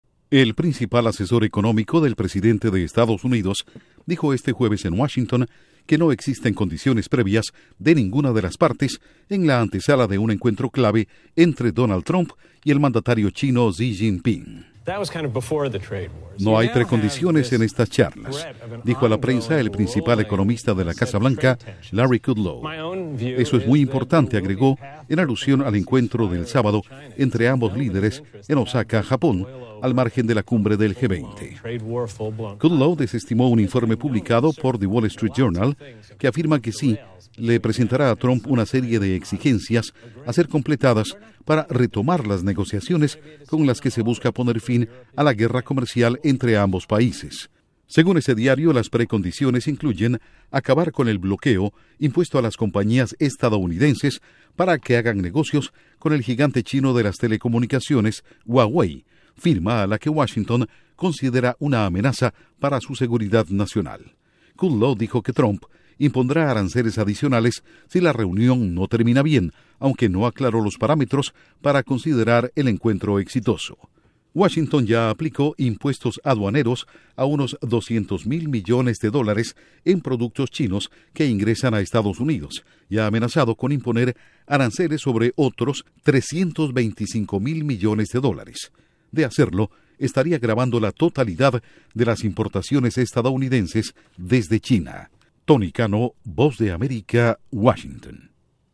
Duración: 1:38 Incluye un audio de asesor de la Casa Blanca